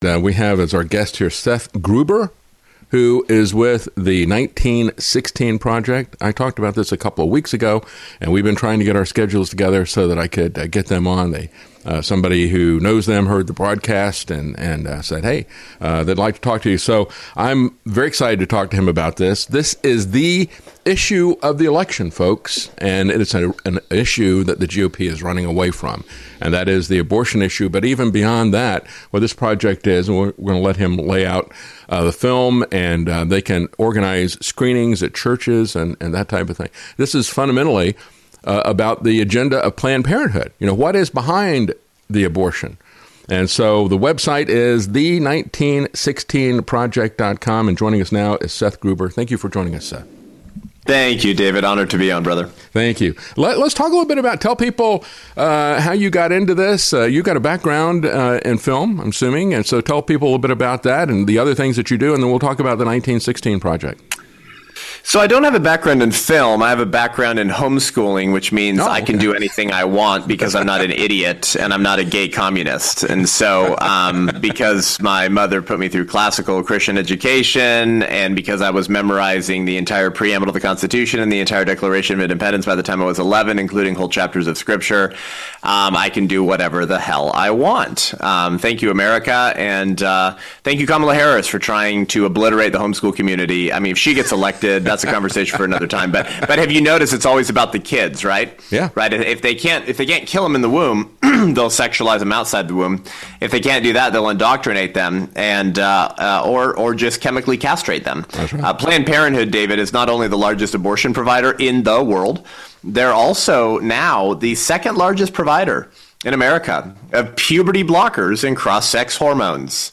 interview-1916-project-history-agenda-of-planned-parenthood.mp3